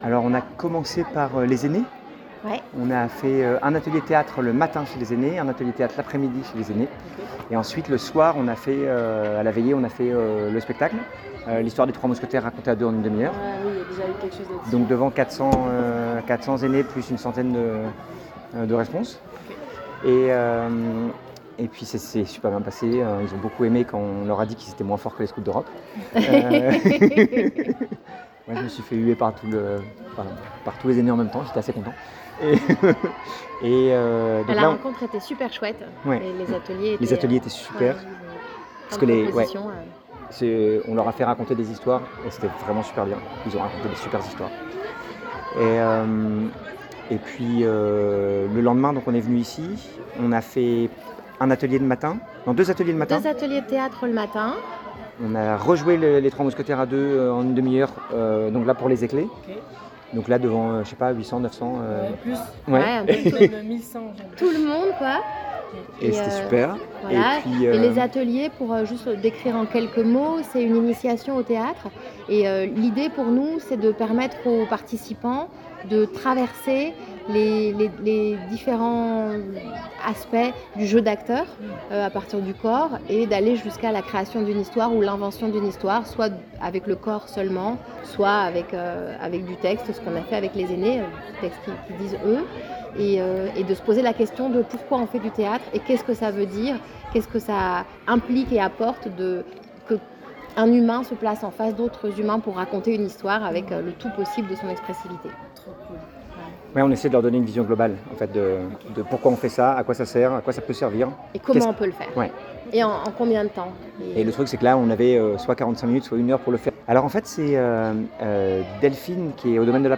Voici un entretien authentique